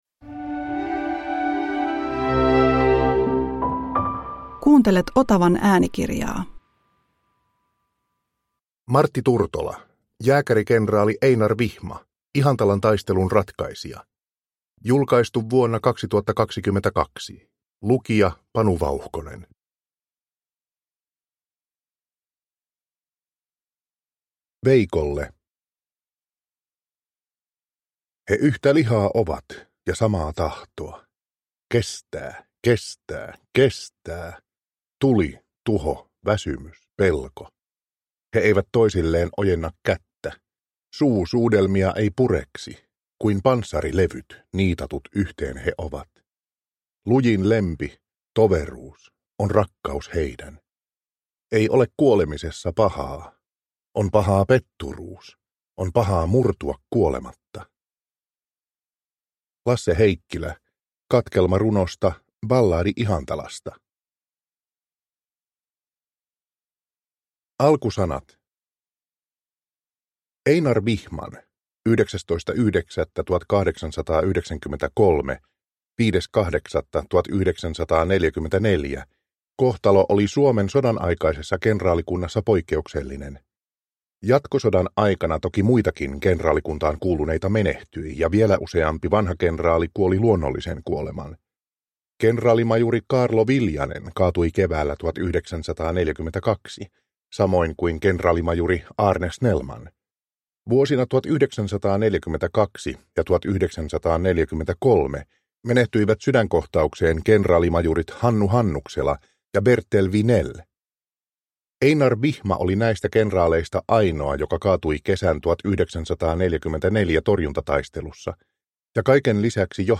Jääkärikenraali Einar Vihma – Ljudbok – Laddas ner